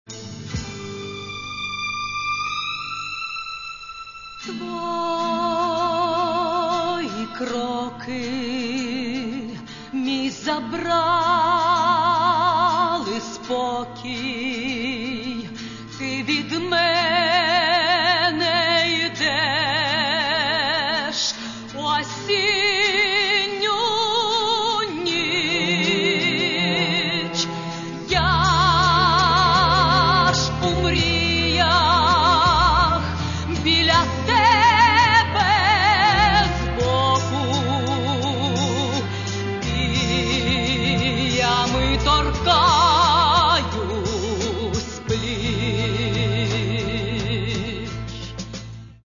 Каталог -> Эстрада -> Поэты и композиторы